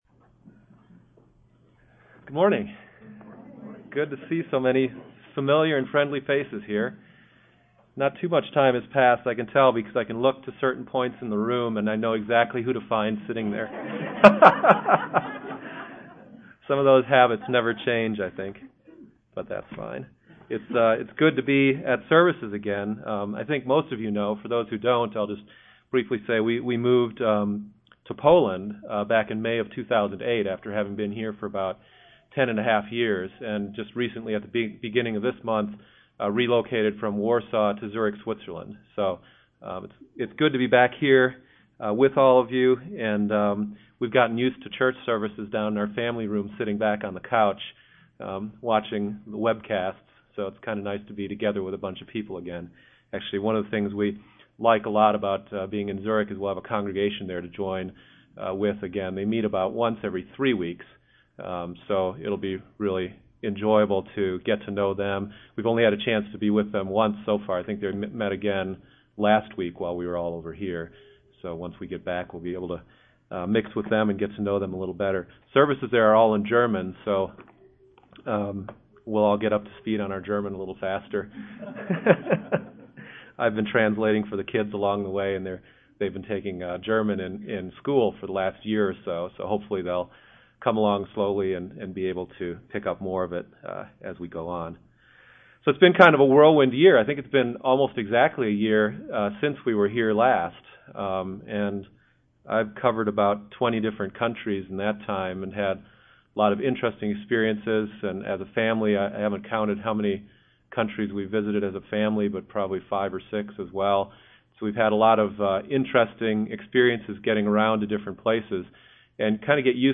Throughout the history of mankind God has called individuals out from familiar and comfortable surroundings making them strangers in other places. There is a good reason for this and a spiritual application for us in the church today. The sermon covers several key points about what it means to be a stranger called out of this world.